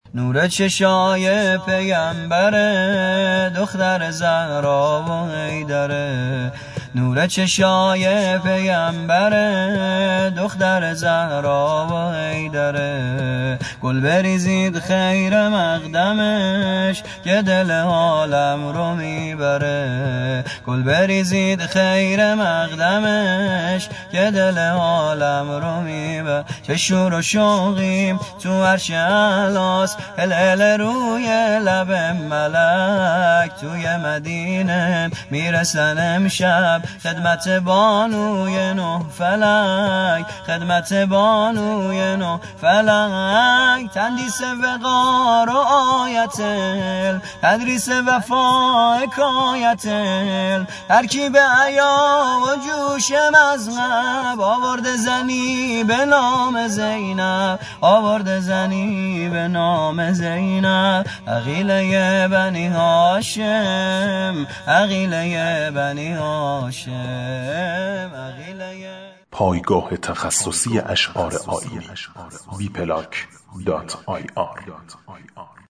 آواز_افشاری